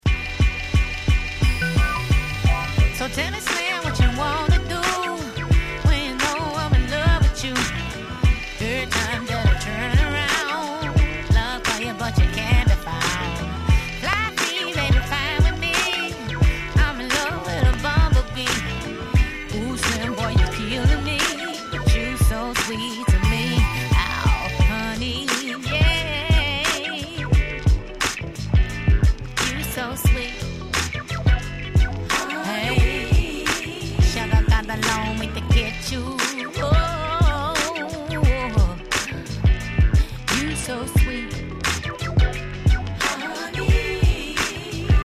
00's Neo Soul ネオソウル